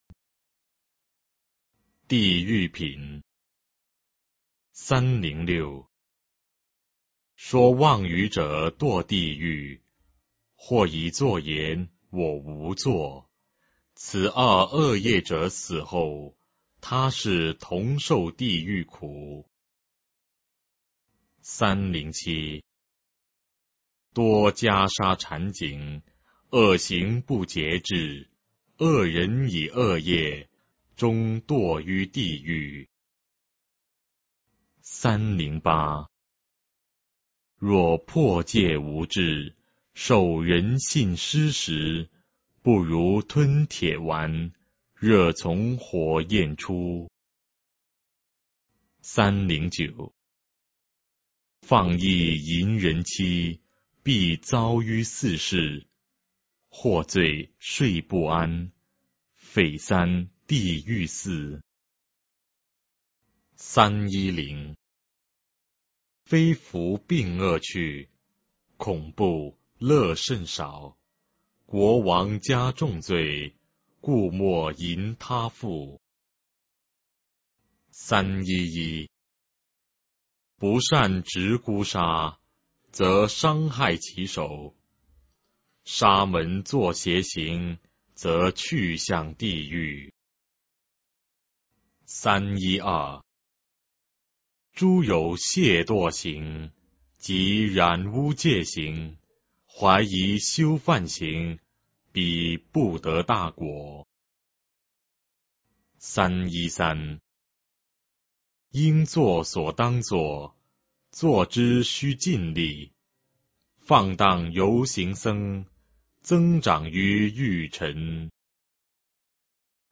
法句经-地狱品 诵经 法句经-地狱品--未知 点我： 标签: 佛音 诵经 佛教音乐 返回列表 上一篇： 法句经-刀杖品 下一篇： 法句经-恶品 相关文章 Om Sai Ram--The Buddhist Monks Om Sai Ram--The Buddhist Monks...